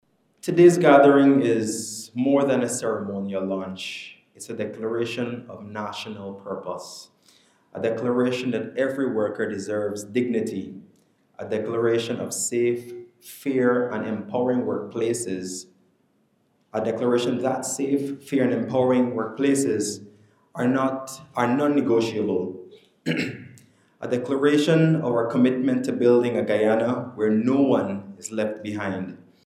Minister of Labour and Manpower Planning, Keoma Griffith